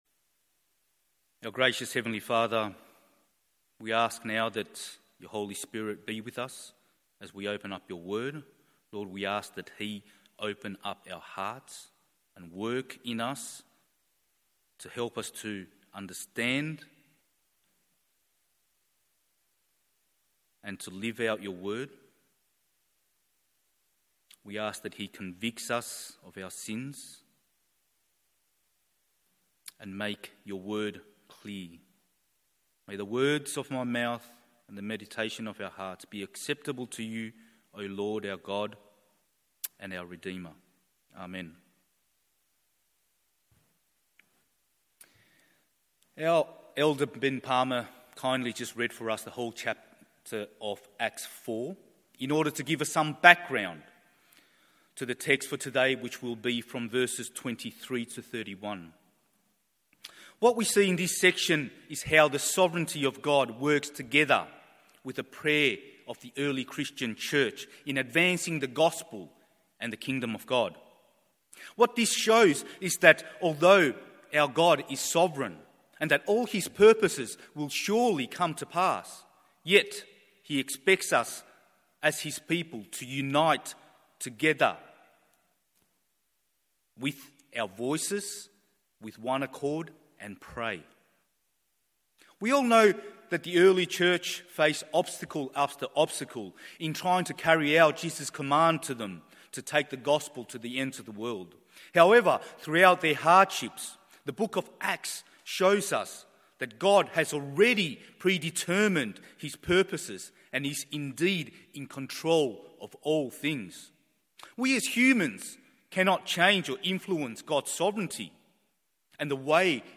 MORNING SERVICE Acts 4:23-31…